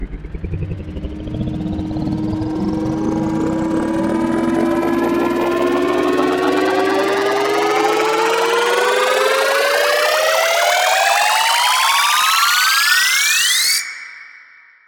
Category: SFX Ringtones